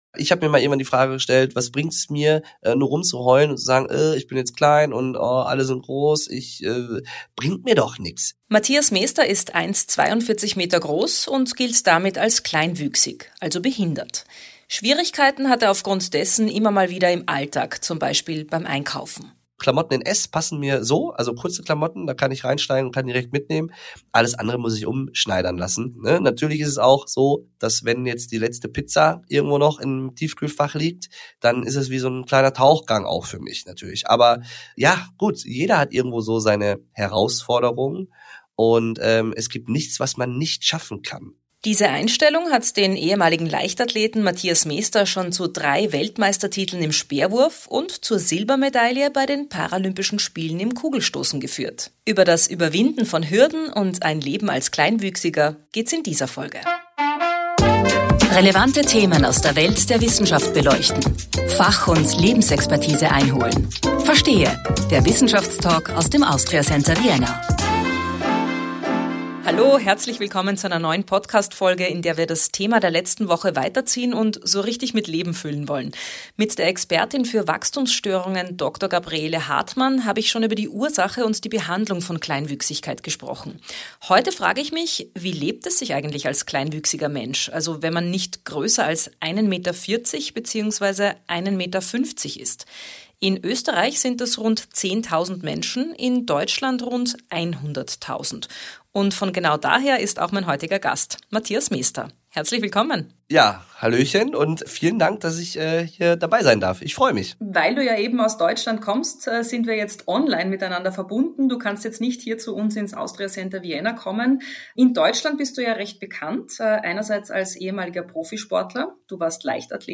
Der Wissenschaftstalk aus dem Austria Center Vienna Podcast